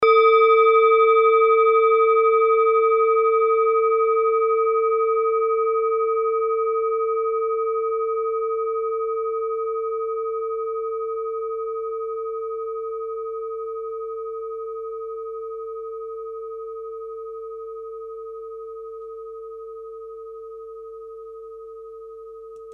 Kleine Klangschale Nr.25
Diese Klangschale ist eine Handarbeit aus Bengalen. Sie ist neu und ist gezielt nach altem 7-Metalle-Rezept in Handarbeit gezogen und gehämmert worden.
Der Lilithton liegt bei 246,04 Hz und ist die 36. Oktave der Umlauffrequenz der Lilith durch den Zodiac. In unserer Tonleiter liegt dieser Ton nahe beim "H".
kleine-klangschale-25.mp3